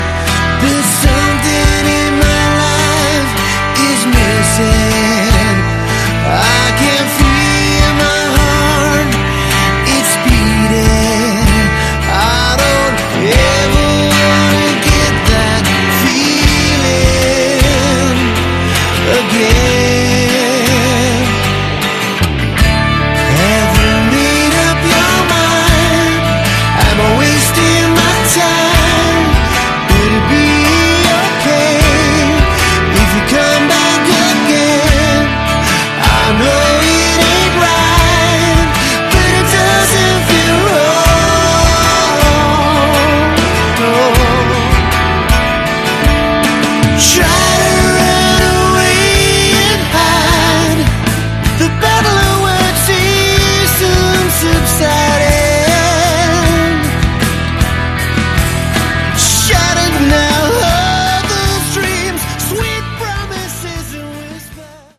Category: Hard Rock
vocals
guitars, backing vocals
drums